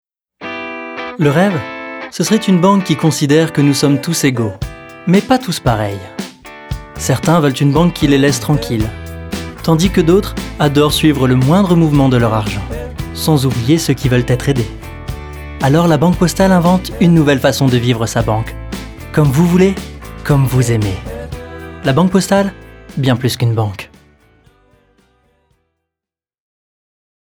Voix off
18 - 40 ans - Baryton